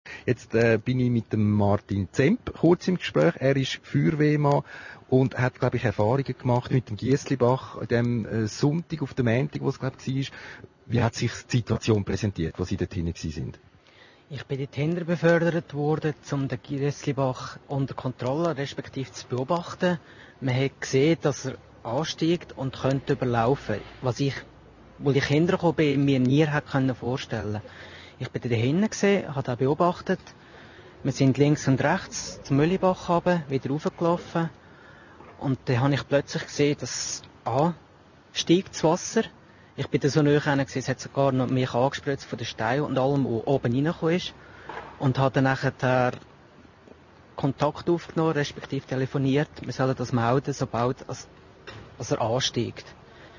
Bericht